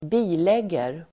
Uttal: [²b'i:leg:er]